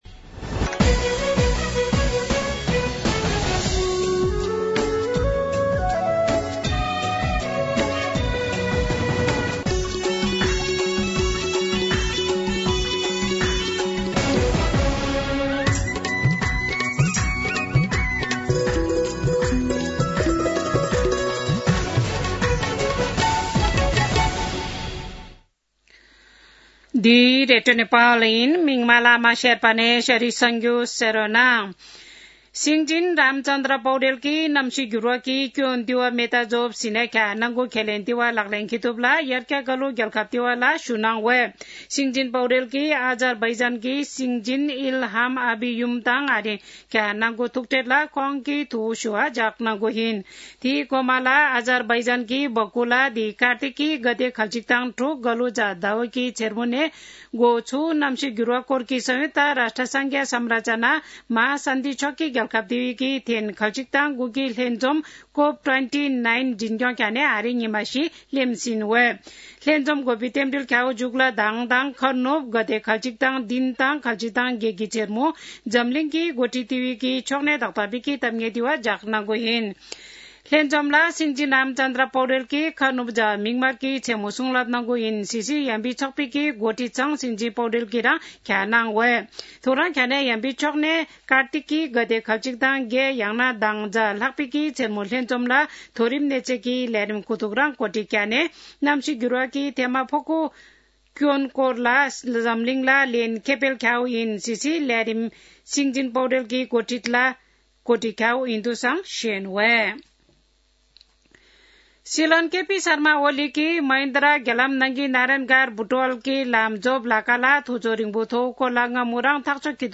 शेर्पा भाषाको समाचार : ३० कार्तिक , २०८१
Sherpa-news.mp3